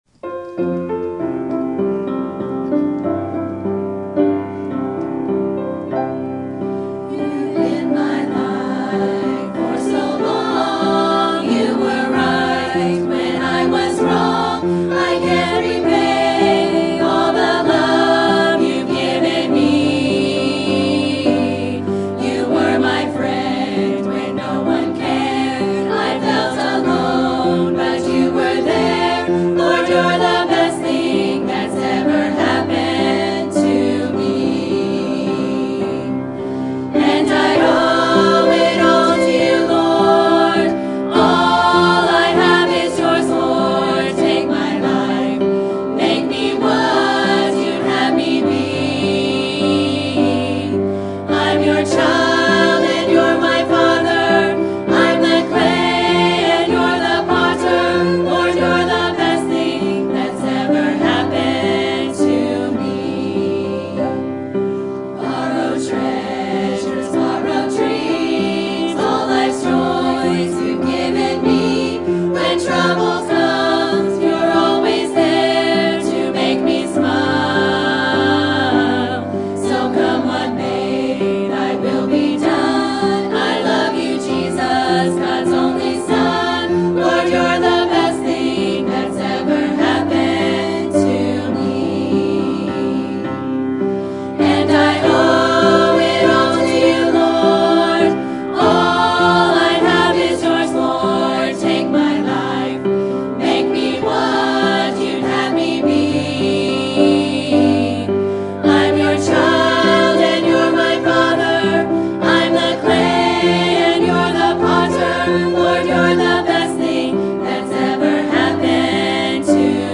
Ladies Group